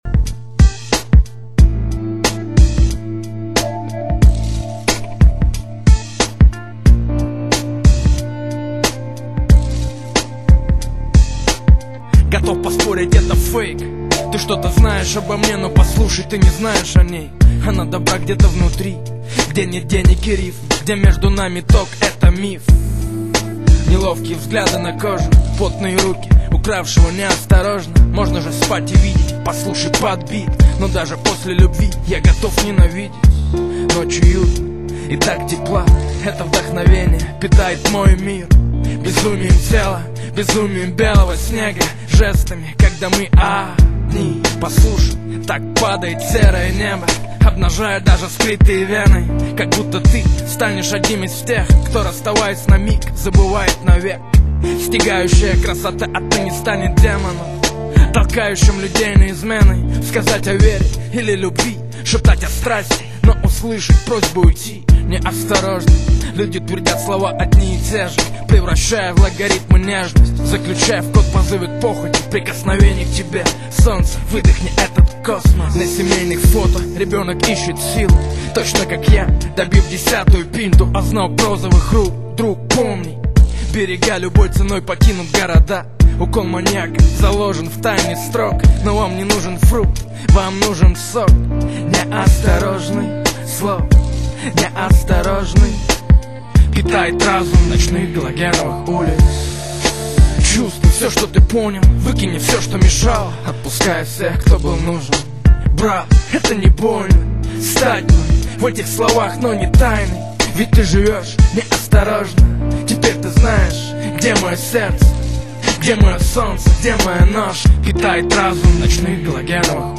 Главная » Файлы » Музыка » РэпЧина